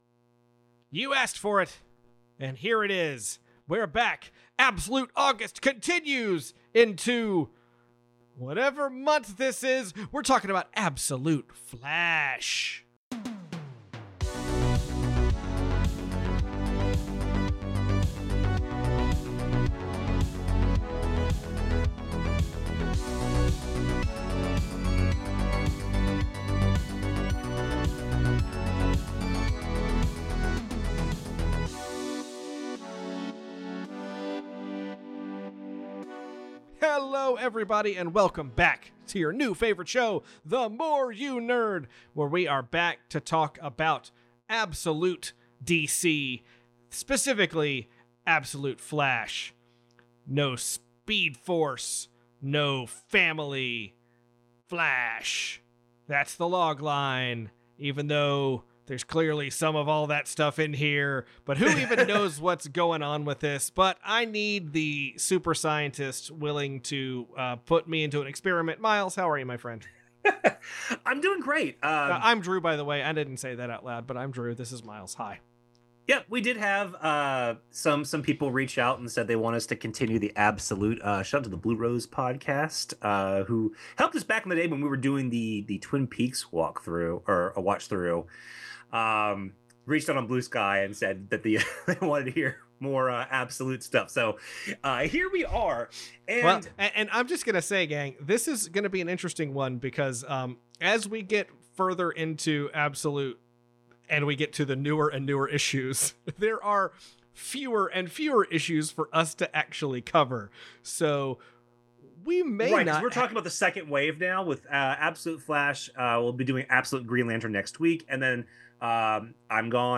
You wanted it, and you’ve got it, we’re back for the next round of Absolute DC! *Please note there is an audio issue in this episode that we unfortunately did not detect while recording.